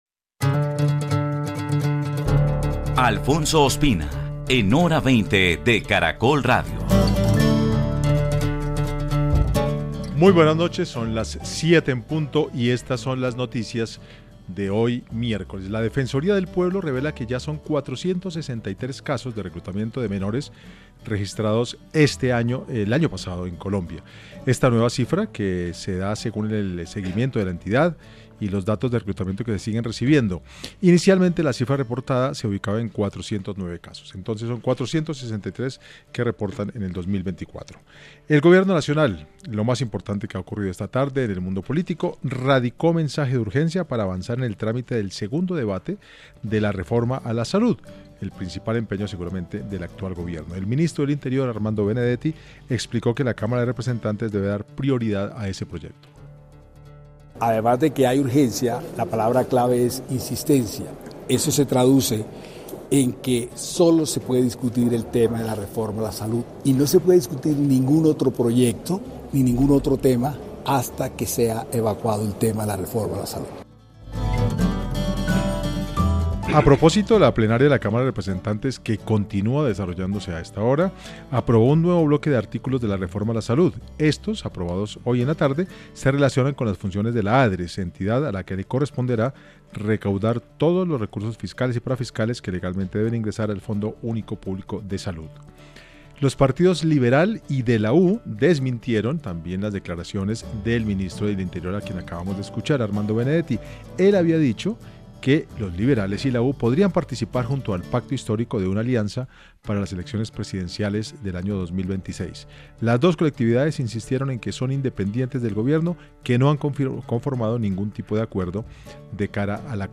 Cinco expertos analizaron el alcance del proyecto de resolución, los retos que representa para la Sabana, los vacíos y las consecuencias para el desarrollo de la región.